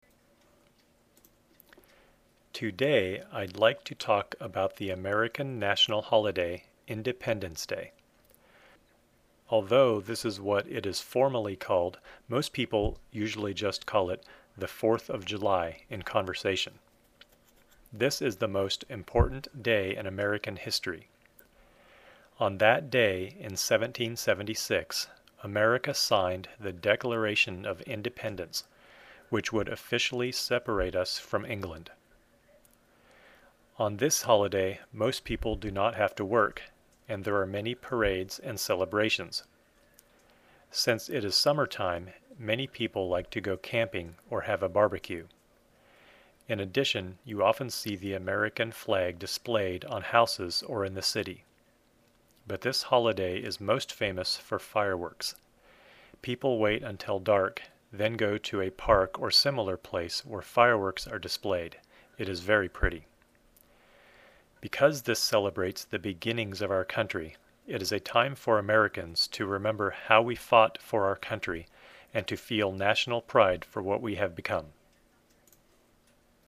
音声はゆっくり録音していますので、聞いてみてくださいね。